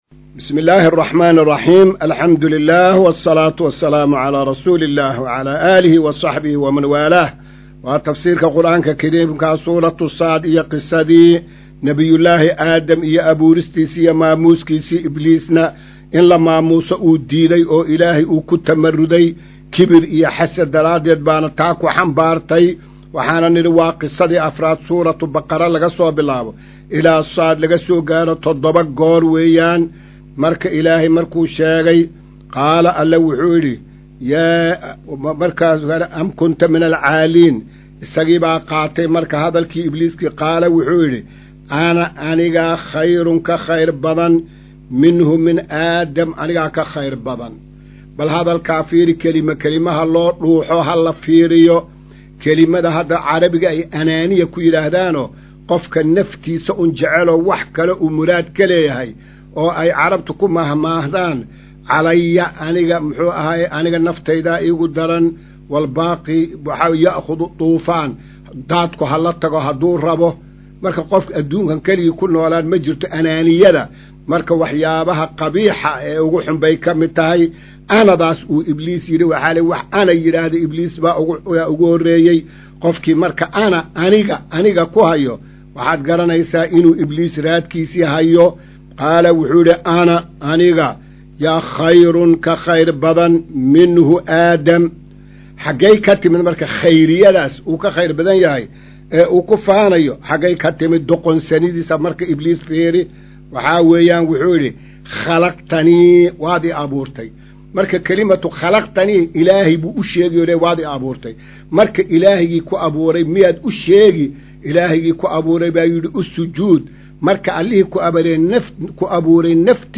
Maqal:- Casharka Tafsiirka Qur’aanka Idaacadda Himilo “Darsiga 218aad”